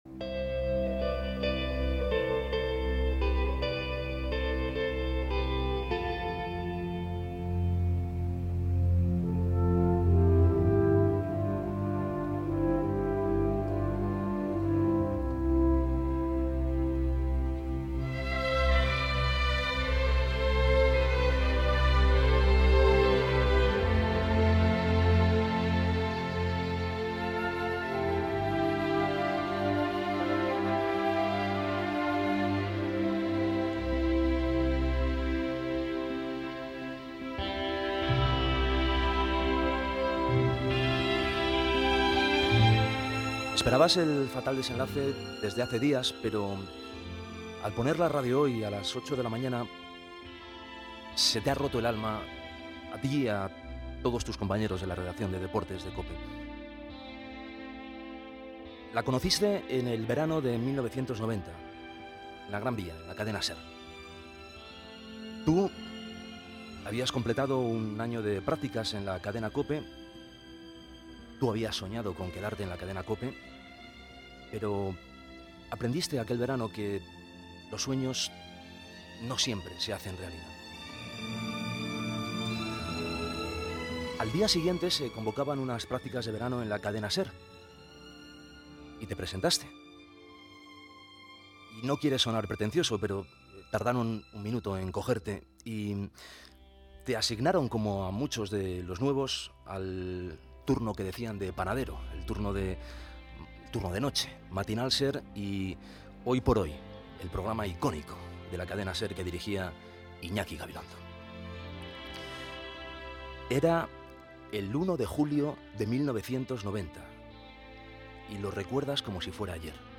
Esportiu
Programa presentat per Juanma Castaño.